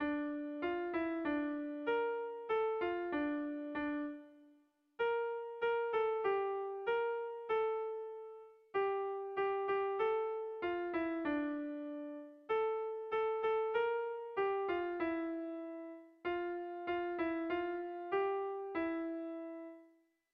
Haurrentzakoa
Lauko txikia (hg) / Bi puntuko txikia (ip)
AB